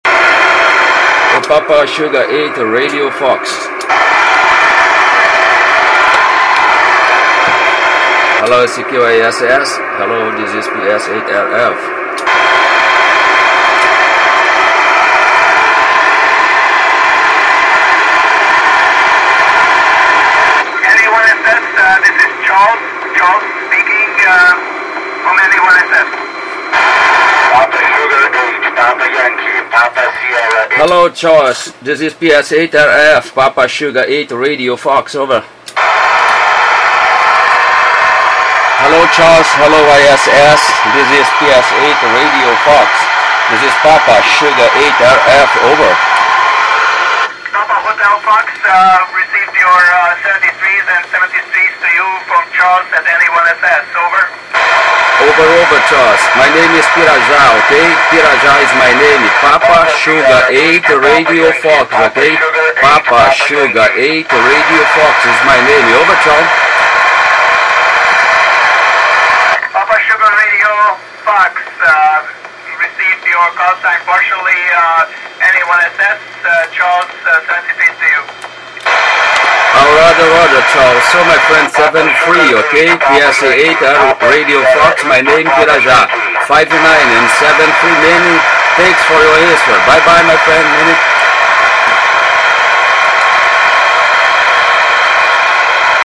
We can hear another station in my city calling for Charles in the downlink frequency.